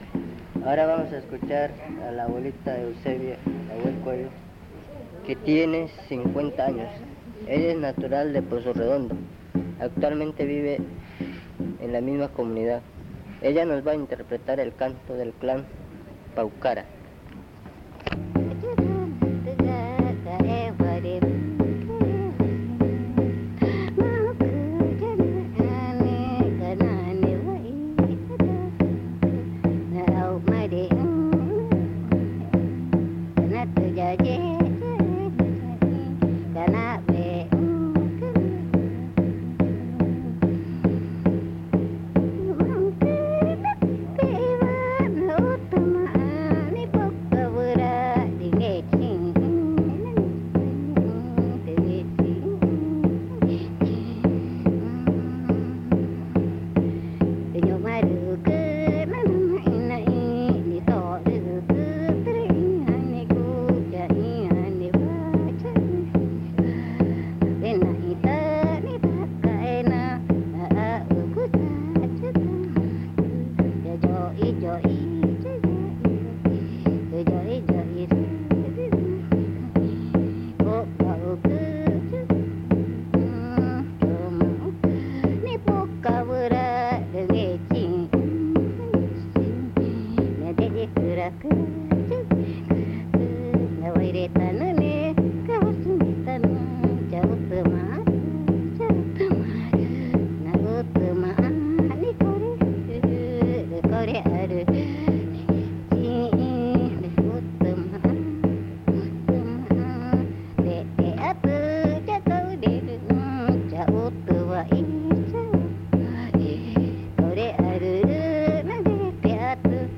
Canto del clan Paucara
Pozo Redondo, Amazonas (Colombia)
La abuela usa el tambor mientras canta.
The elder uses a drum while singing.